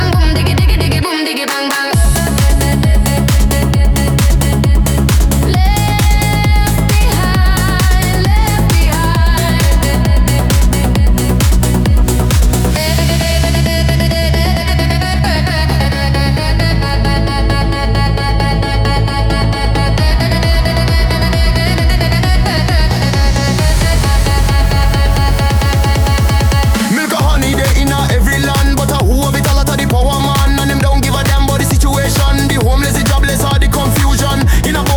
Электроника — это про будущее, которое уже здесь.
Electronic
Жанр: Электроника